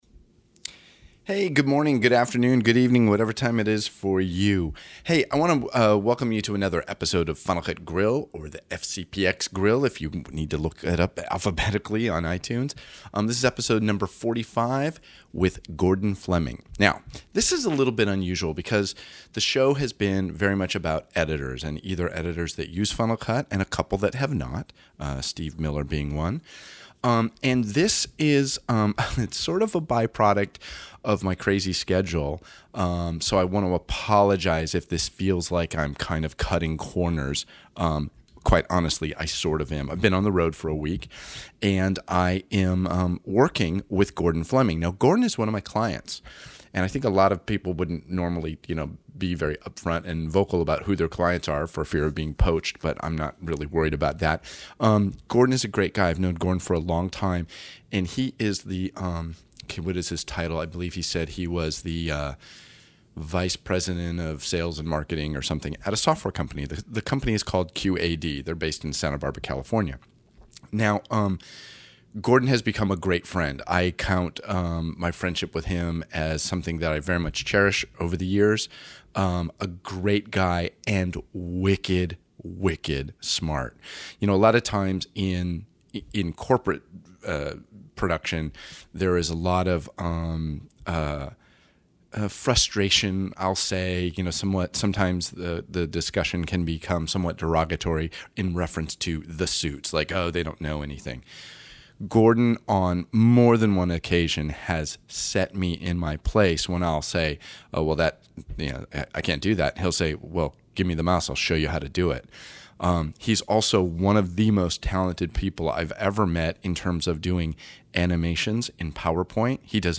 I think you’re going to enjoy this conversation.